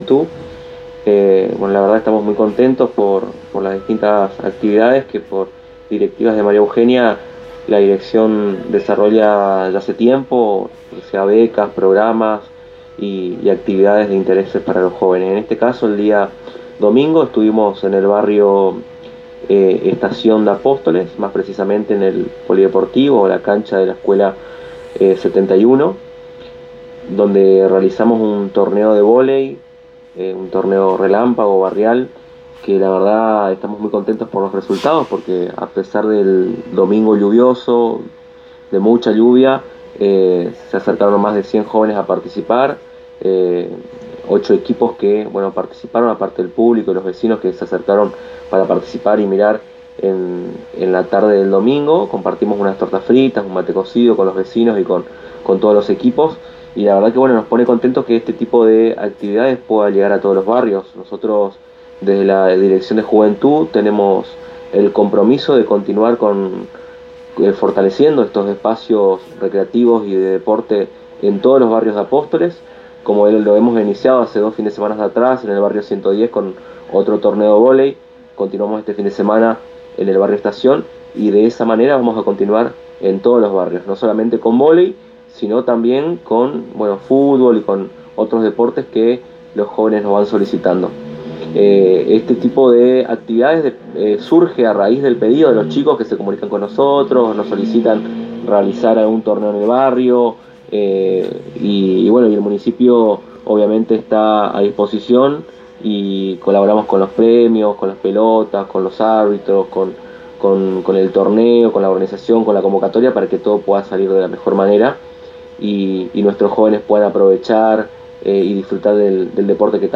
El Director de la Juventud Municipal Aldo Muñoz en charla con la ANG y FM Éxito resaltó el apoyo, impulso que les brinda la Intendente como la libertad que les brinda para dar paso a la creatividad.